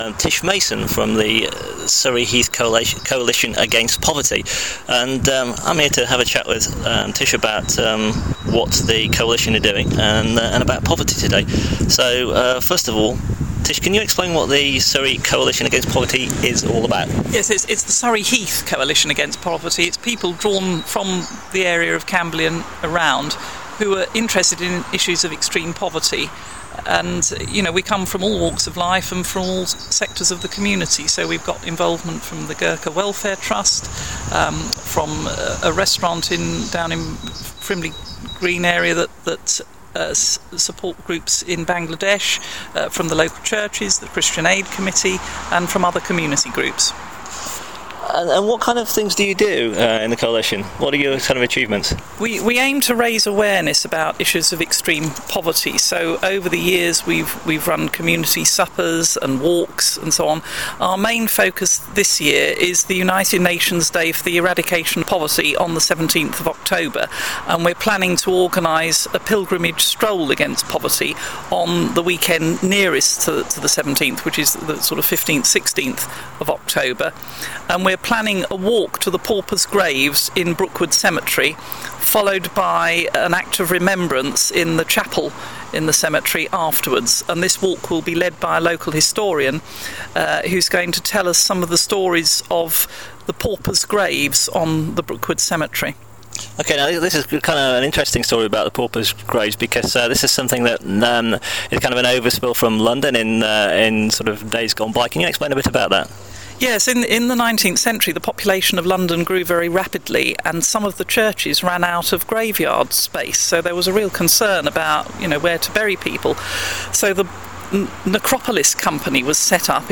Interview: Surrey Heath Coalition Against Poverty